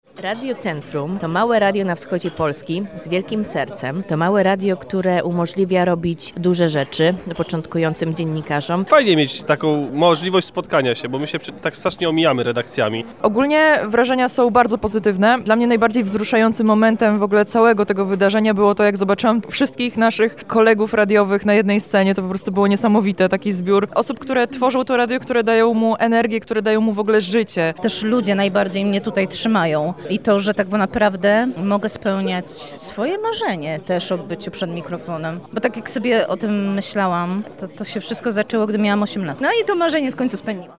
Swoimi wrażeniami podzielili się również nasi radiowi koledzy i koleżanki:
SONDA RADIOWCY